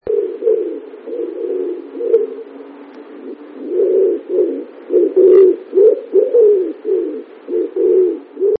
Grzywacz - Columba palumbus
grzywacz.mp3